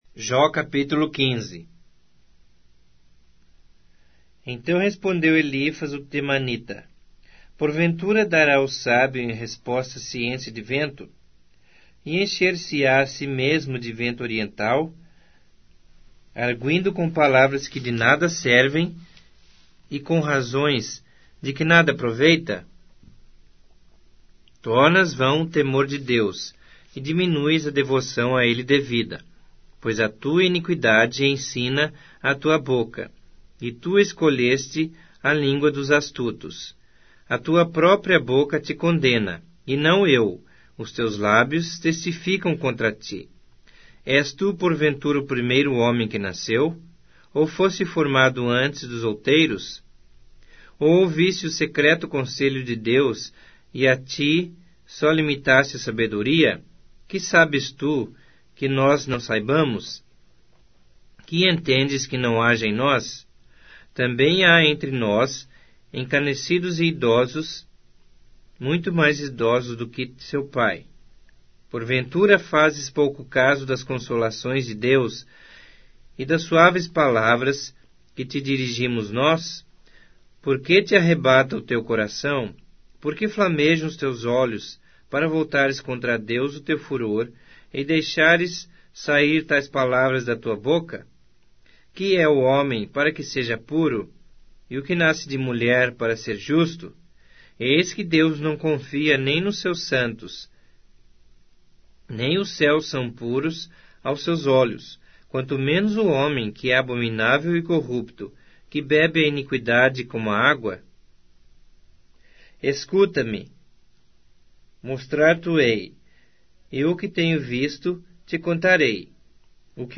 Bíblia Sagrada Online Falada